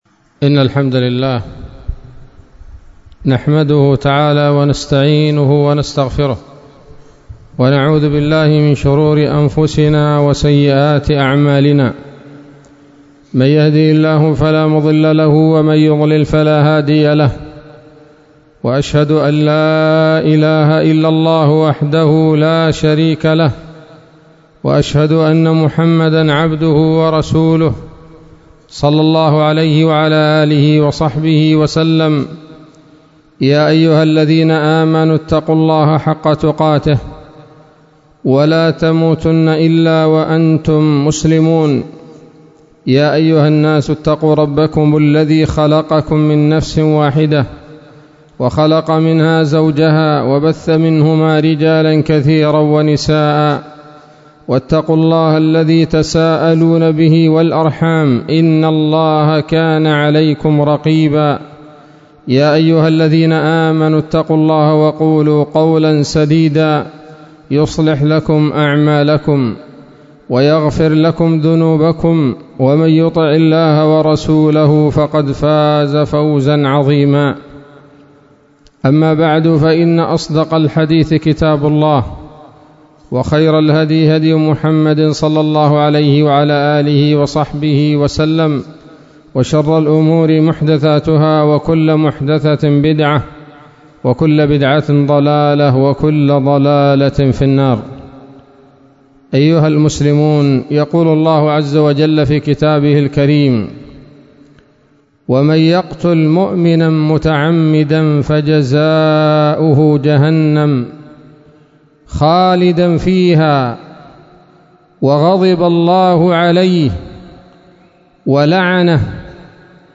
خطبة جمعة بعنوان
22 شعبان 1443 هـ، دار الحديث السلفية بصلاح الدين